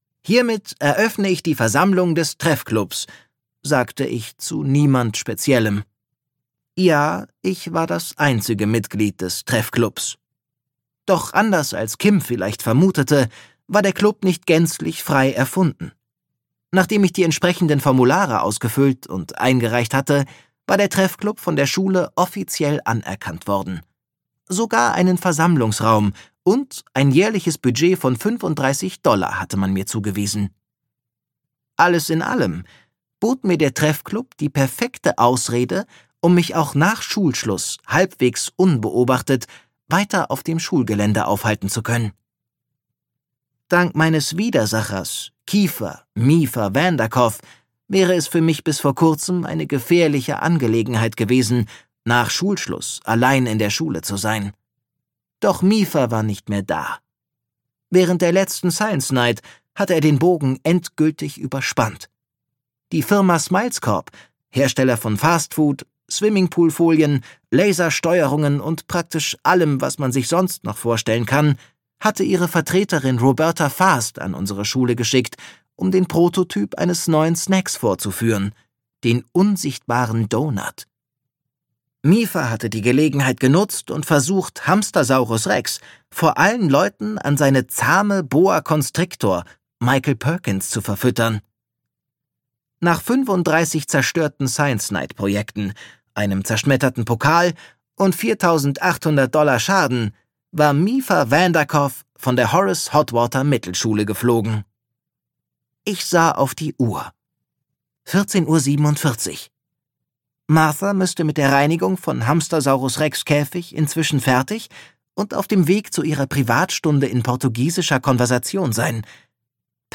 Hamstersaurus Rex 2: Hamstersaurus Rex gegen Eichhörnchen Kong - Tom O' Donnell - Hörbuch